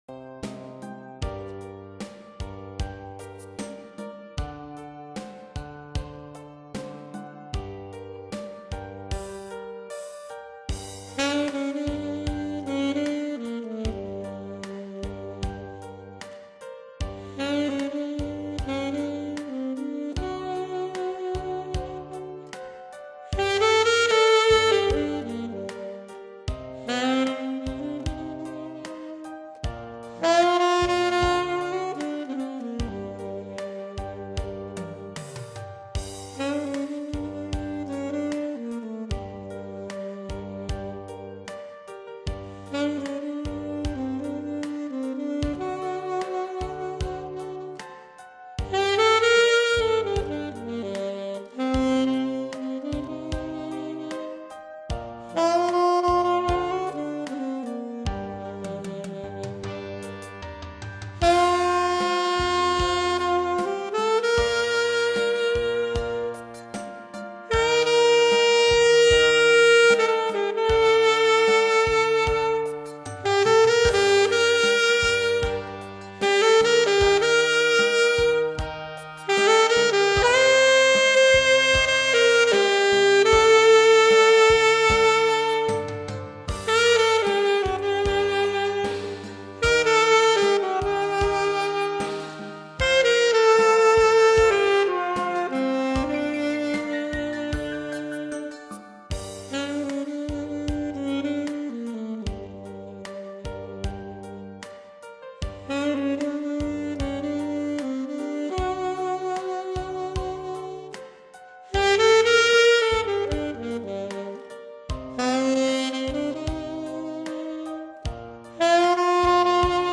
아마추어의 색소폰 연주